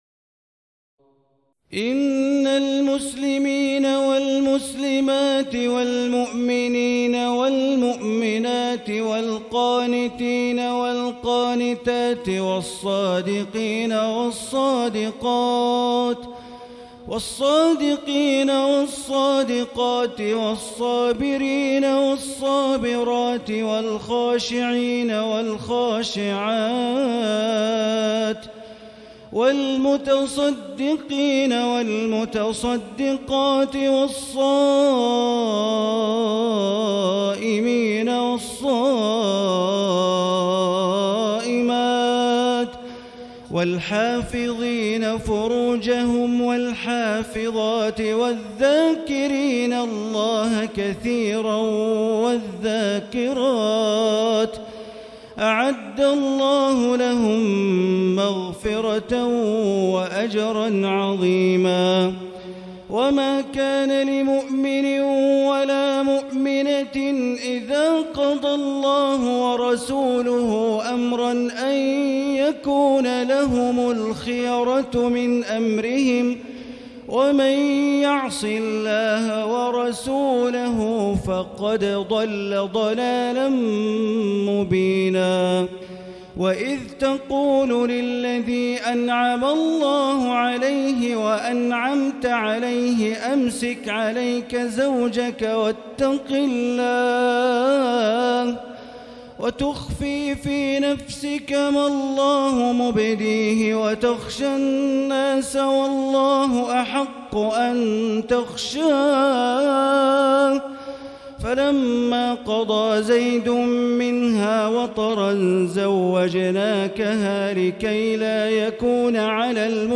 تراويح ليلة 21 رمضان 1439هـ من سور الأحزاب (35-73) وسبأ (1-23) Taraweeh 21 st night Ramadan 1439H from Surah Al-Ahzaab and Saba > تراويح الحرم المكي عام 1439 🕋 > التراويح - تلاوات الحرمين